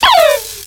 Cri de Lixy dans Pokémon X et Y.